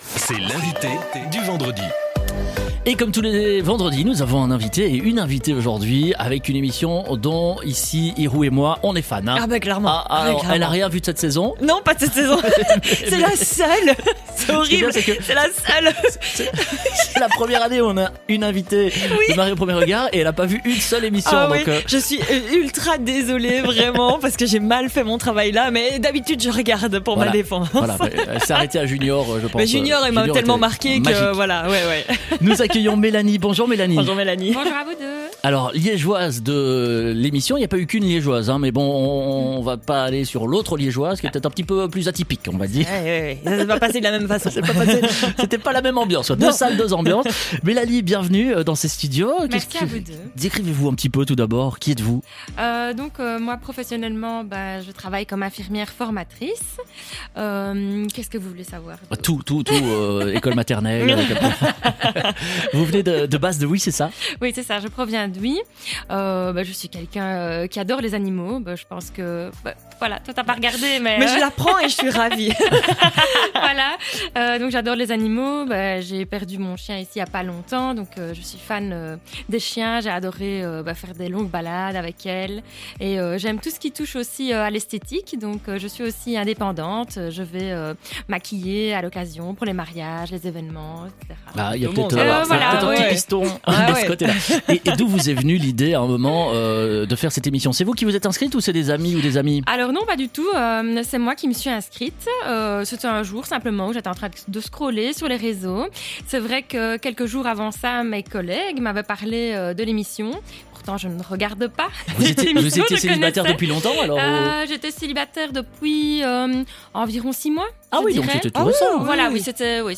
était l'invitée du Wake Up Liège ! Elle nous a parlé des "off" de l'émission, de son stress, et de son aventure exceptionnelle qu'elle a vécu.